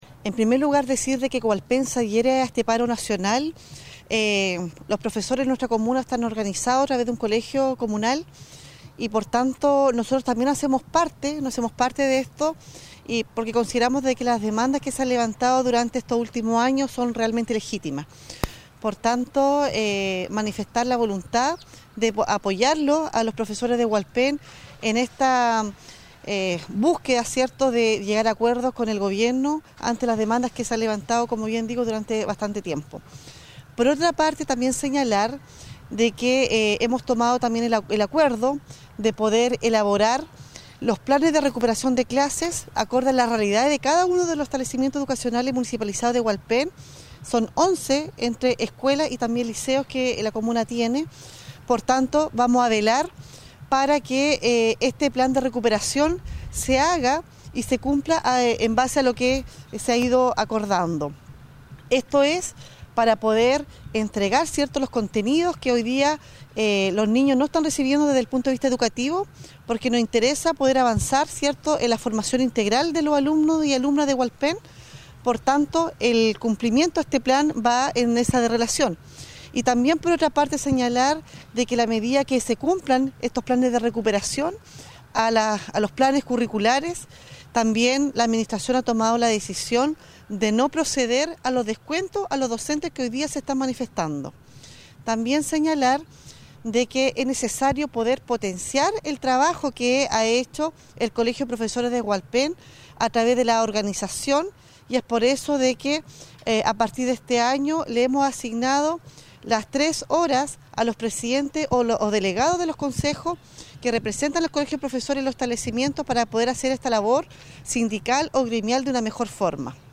CUÑAS-REUNION-CON-PROFESORES.mp3